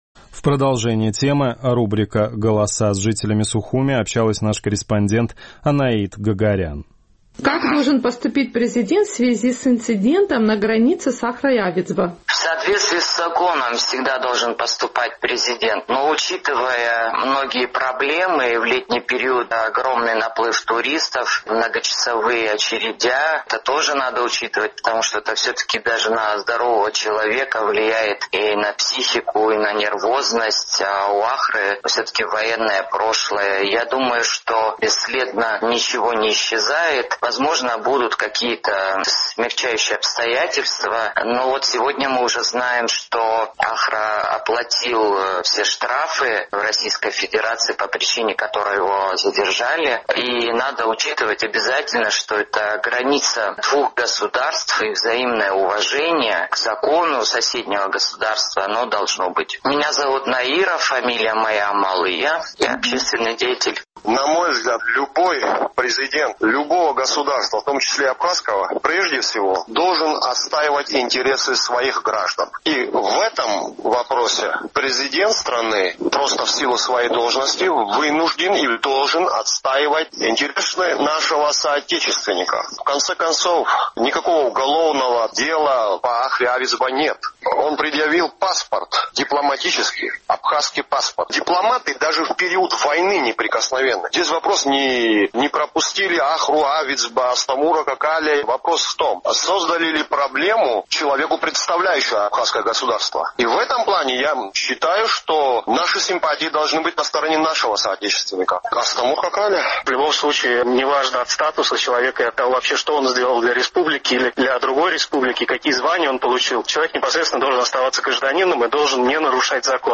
Инцидент с помощником абхазского президента Ахрой Авидзба, который протаранил ворота на КПП «Псоу», пока не повлек за собой никаких действий. Какой должна быть реакция абхазской стороны? Мнения в нашем традиционном сухумском опросе разошлись.